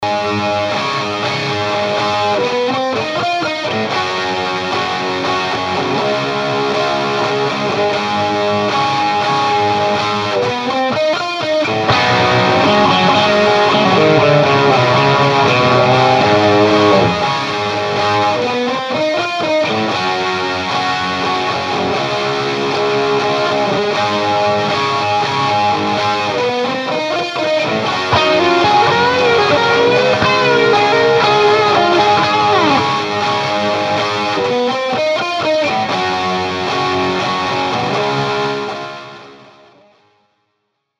TONE SAMPLES - All clips plugged straight into amp unless noted.
1/2 watt, intentionally over-pushing an already pushed amp w/ boss pedal
for that classic 80's smooth el34 grind which is impossible to get without slamming
the power section. It wont mush out and can handle even more gain!